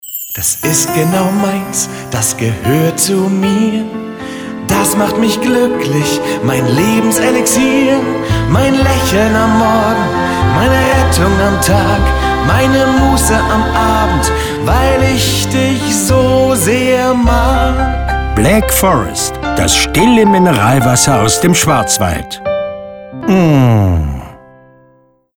Black Forest – Radiowerbung 2019 – Carpe Diem Studios
Sprecher : Arne Elsholtz (Tom Hanks Synchronsprecher)